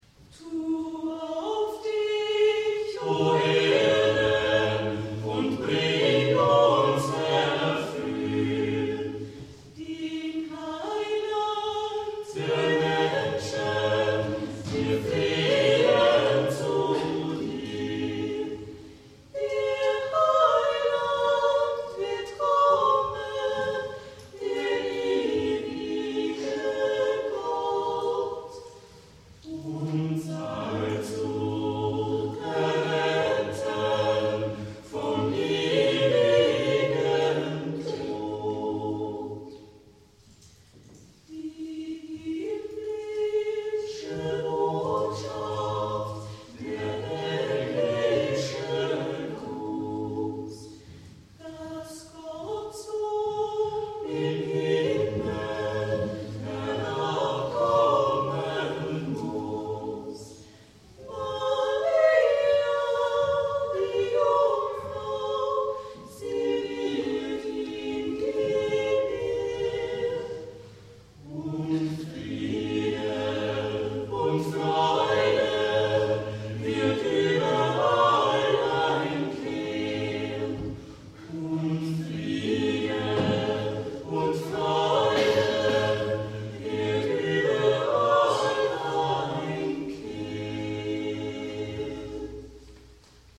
Von Renaissance und Romantik über Beatles-Songs und Volkslieder bis hin zu Pop und zeitgenössischen Stücken ist alles vertreten – kurz gesagt, A-Cappella-Musik aus 400 Jahren.